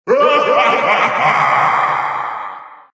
File:Master Hand Laugh (Ultimate).oga
Voice clip from Super Smash Bros. for Wii U and Nintendo 3DS.
Master_Hand_Laugh_(Ultimate).oga.mp3